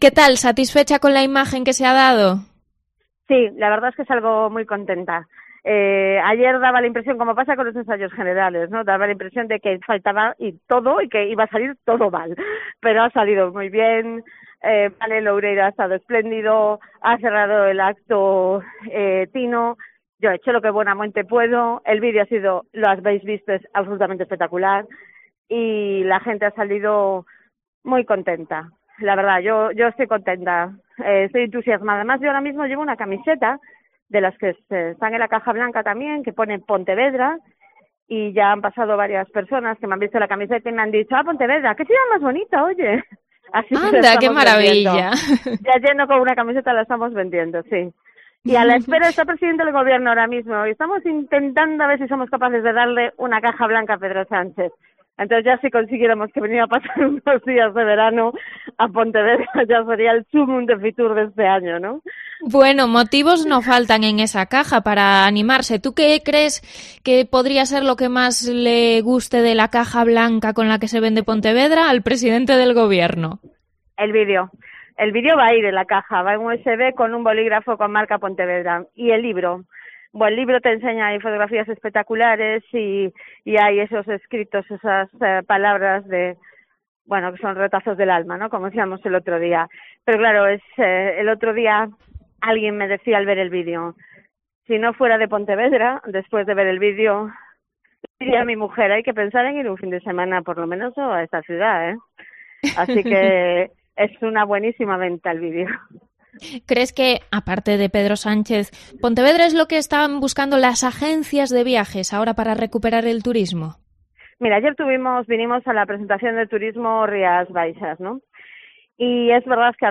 La concejala de turismo de Pontevedra, Yoya Blanco , ha atendido la llamada de COPE desde FITUR en el momento en el que concejales socialistas como Tino Fernández intentaban convencer a la seguridad del presidente del Gobierno para poder entregarle la caja blanca que la ciudad ha llevado a la feria para promocionar la capital.